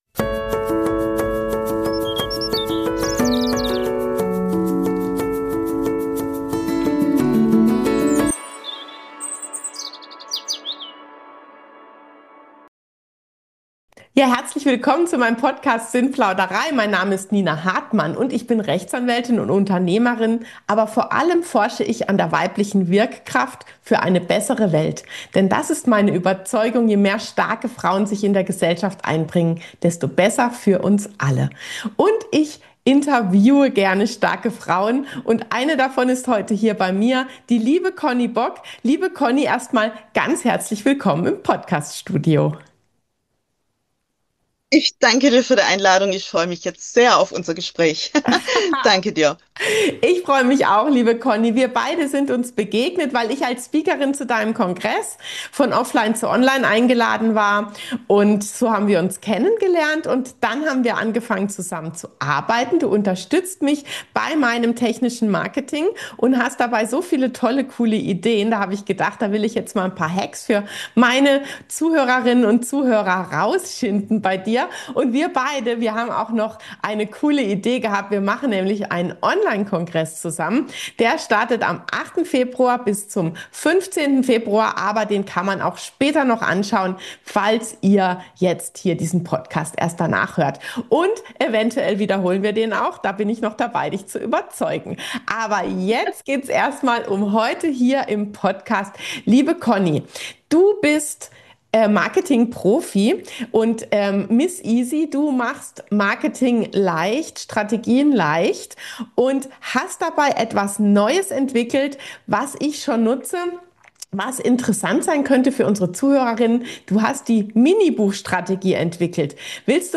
Kreatives Marketing 2026: Mini-Buchstrategie, Silent Seller System & authentische Positionierung – Interview